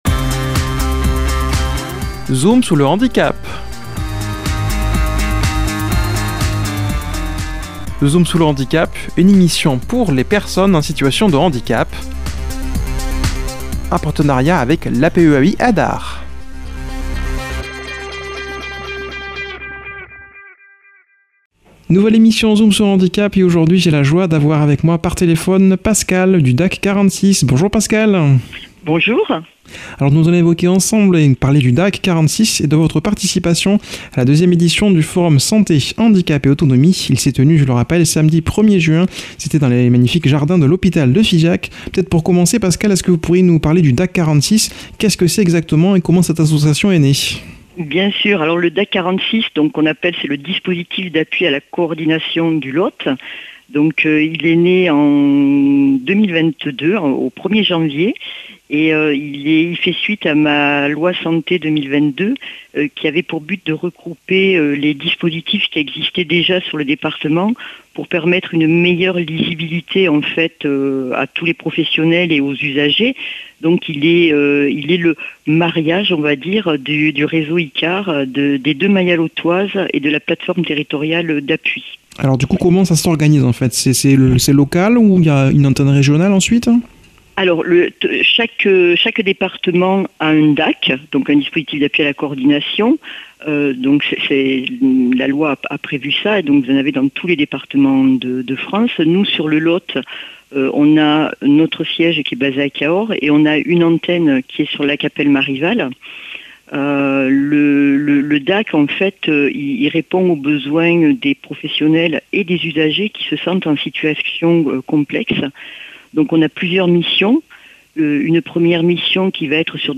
a comme invitée par téléphone